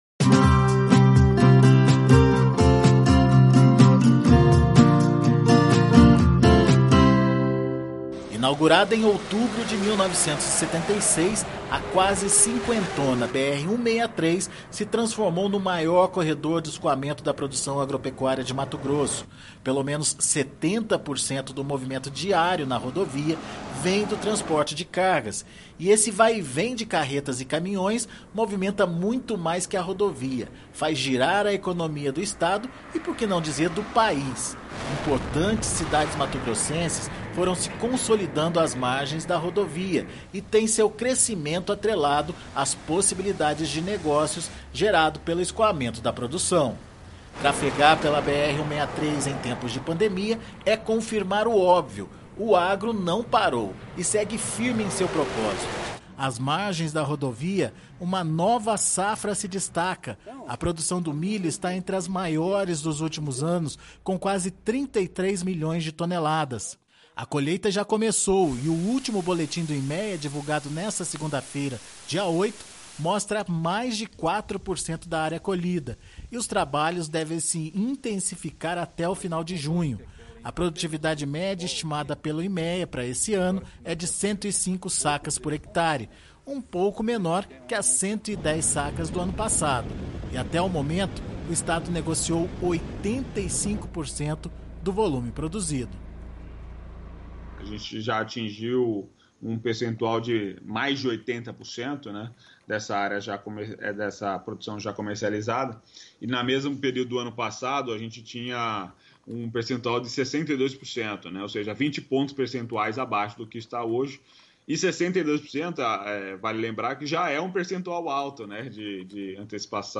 A produção brasileira ao longo da BR-163. Na primeira reportagem, o início da colheita do milho no MT